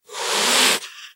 hiss3.mp3